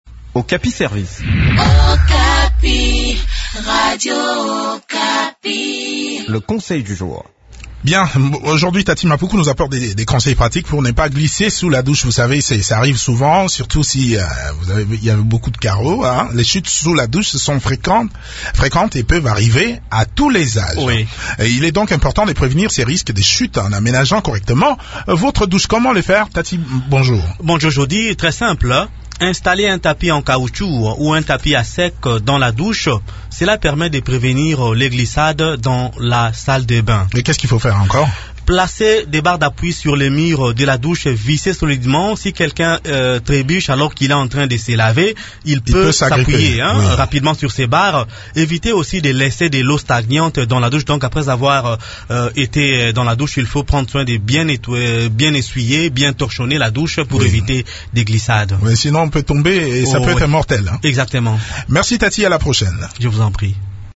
chronique